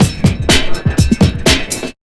NYC125LOOP-L.wav